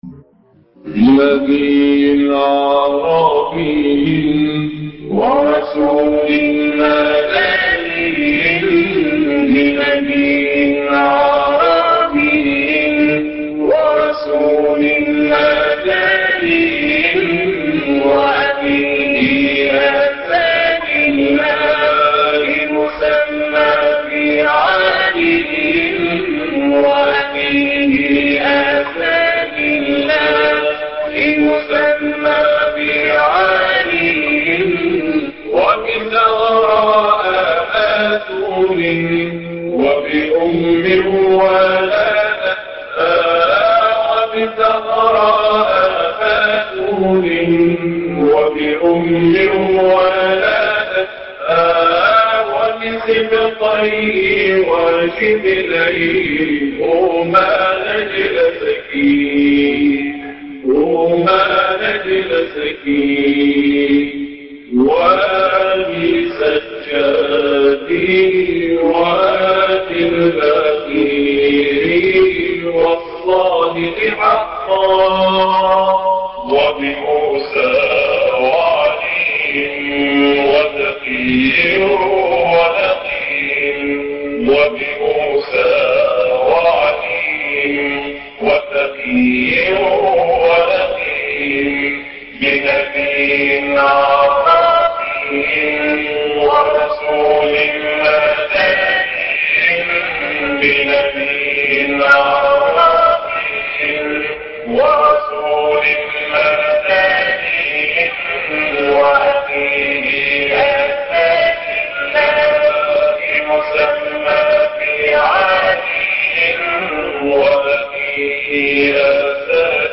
بنبي عربي - نغمة البيات - لحفظ الملف في مجلد خاص اضغط بالزر الأيمن هنا ثم اختر (حفظ الهدف باسم - Save Target As) واختر المكان المناسب